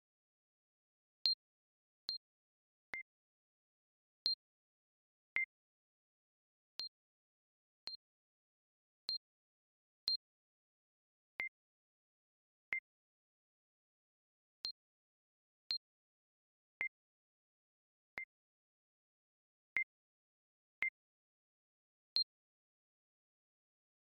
High pitch sound for forehand and low pitch for backhand.
forehand-backhand.mp3